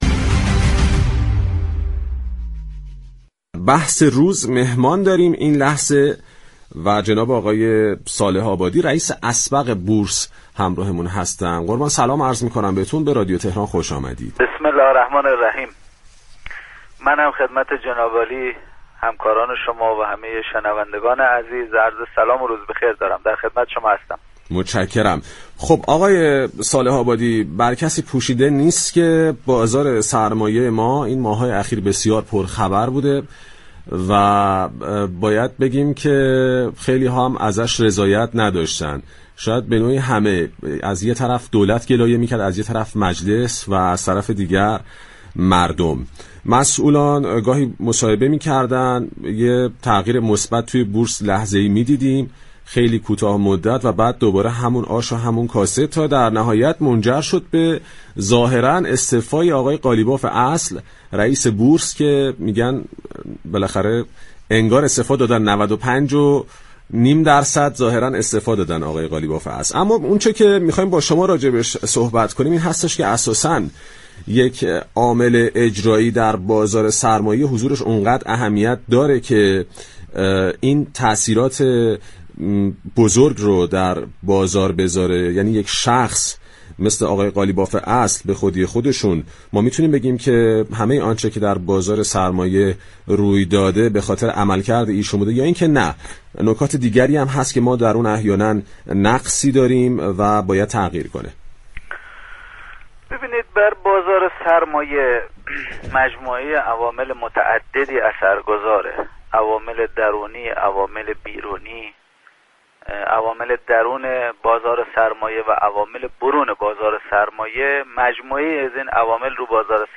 به گزارش پایگاه اطلاع رسانی رادیو تهران، فراز و نشیب های چندماهه اخیر بورس ، استعفای حسن قالیباف اصل رئیس سازمان بورس و از همه مهمتر عدم رضایتمندی اغلب سهامداران بورس از موضوعاتی است كه حوزه اقتصاد كشور را تحت تاثیر قرار داده است در همین راستا برنامه بازار تهران 4 بهمن با علی صالح آبادی رئیس اسبق سازمان بورس گفتگو كرد.